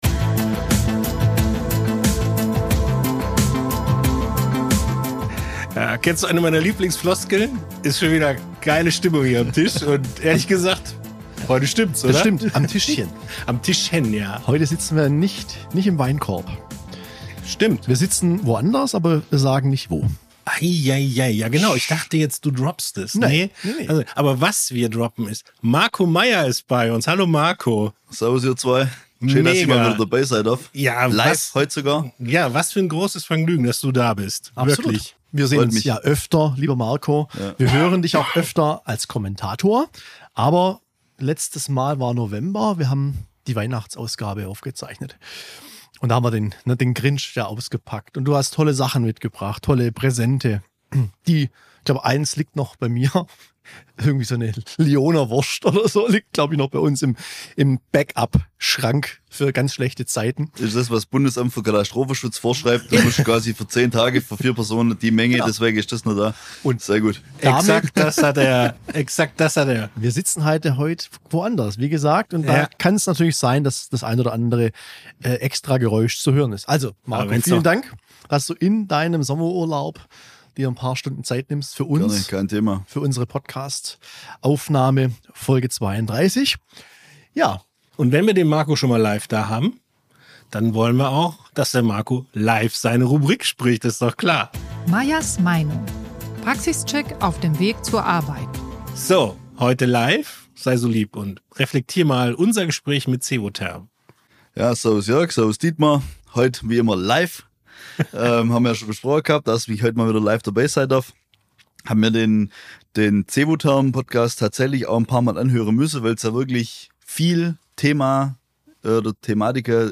Und warum dauert es oft Jahre bis Jahrzehnte, bis sich ein neuer Standard in der Fläche etabliert hat? Fragen über Fragen, denen sich das Trio in seiner bekannt lockeren Art widmet.